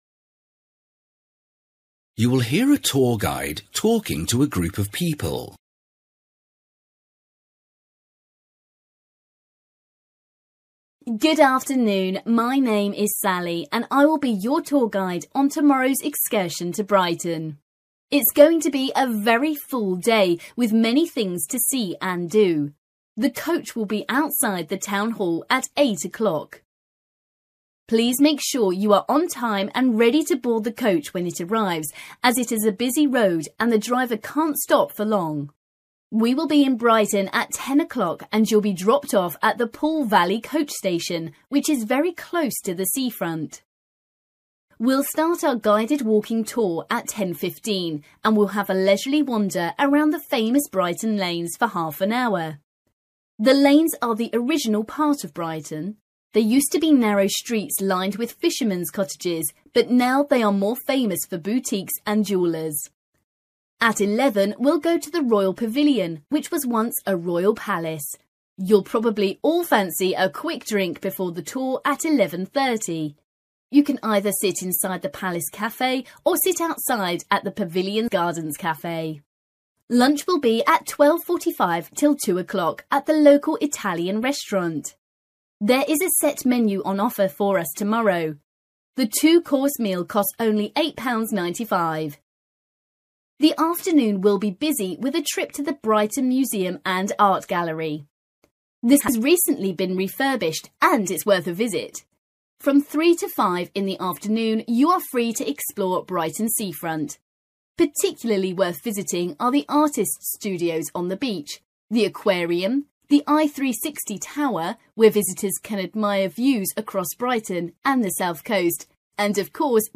You will hear a tour guide talking to a group of people.